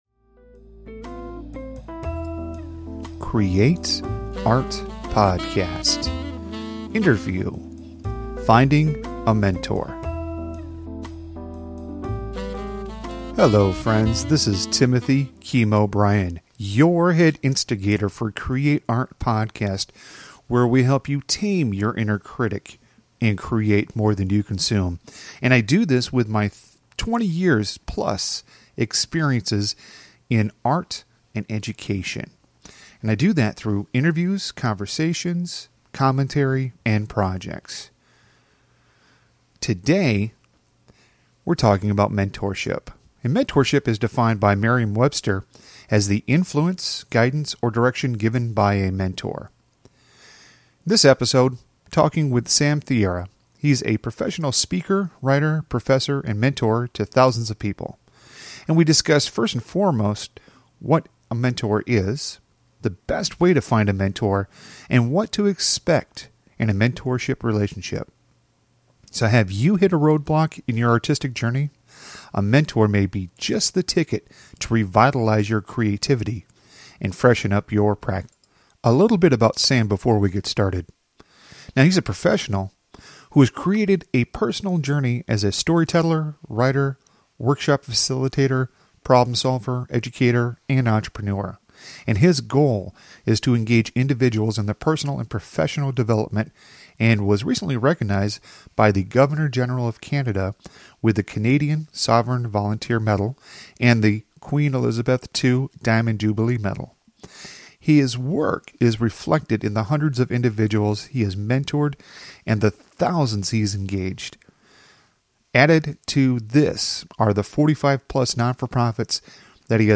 Interview: Finding A Mentor
interview-finding-a-mentoran8ko.mp3